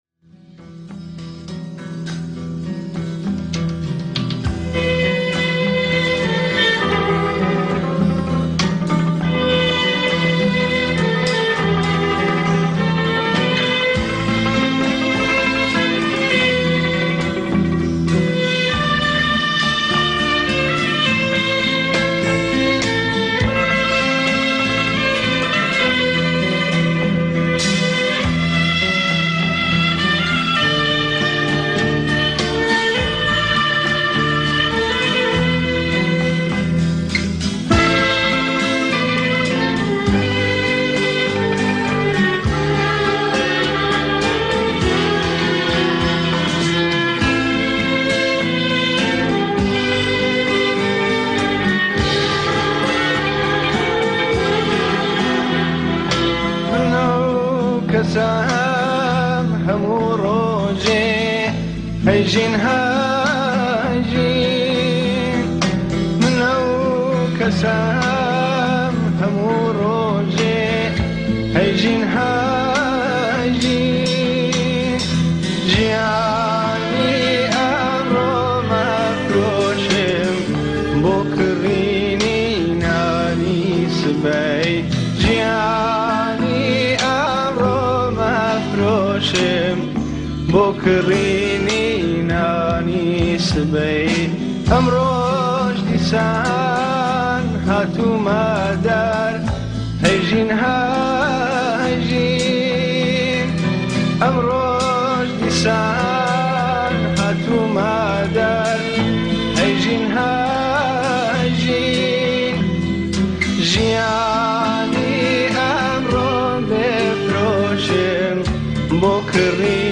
آهنگ کردی شاد